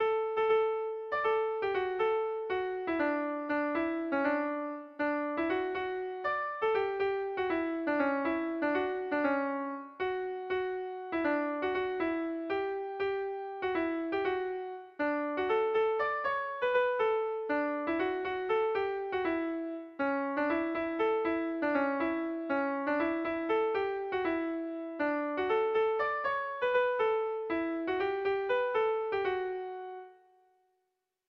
Zorioneko orduan - Melodías de bertsos - BDB.
ABDEF...